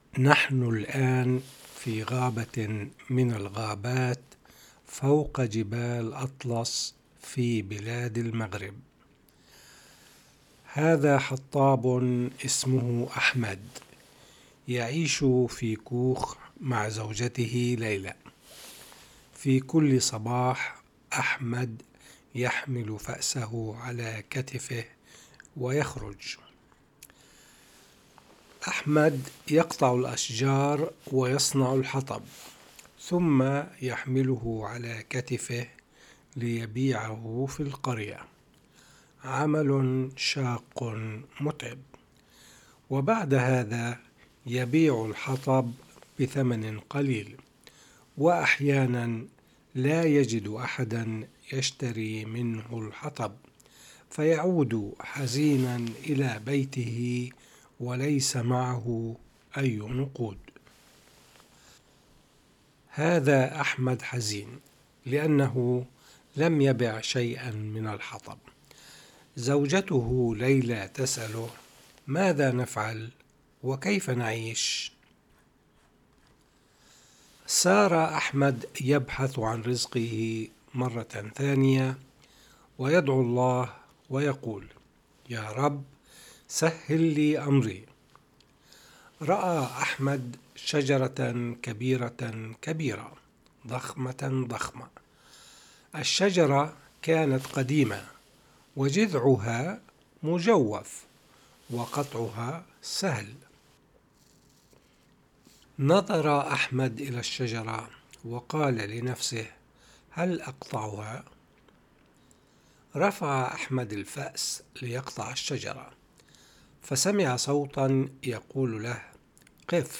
القراءة